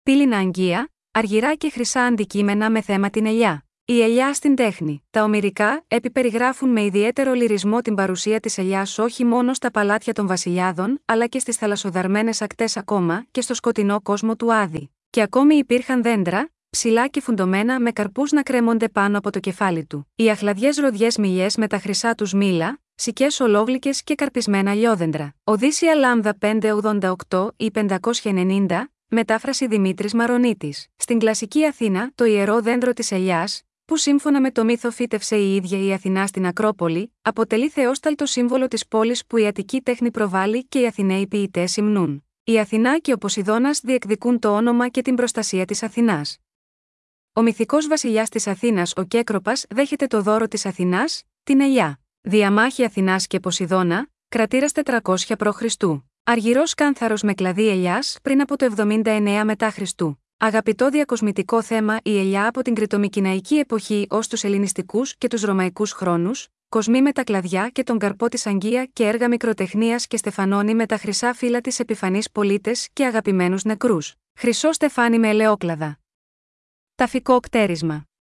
Ηχητική ξενάγηση